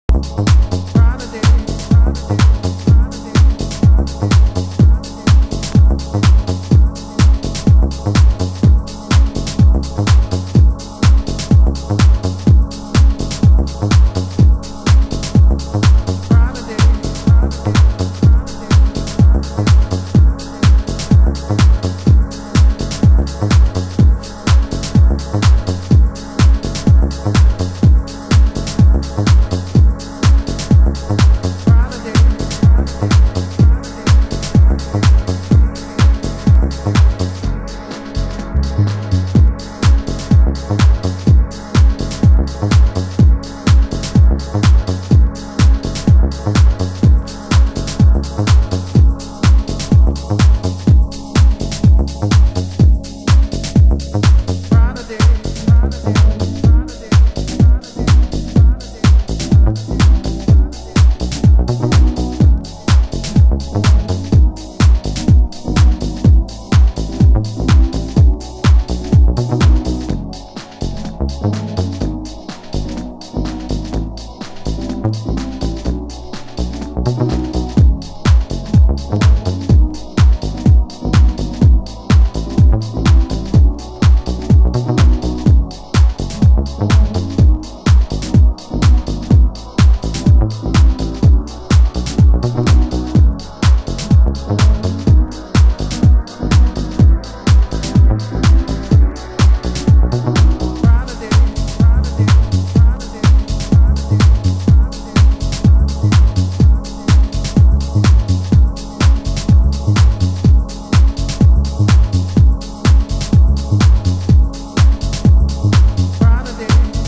盤質：少しチリパチノイズ有　　ジャケット：白無地のインナースリーヴのみ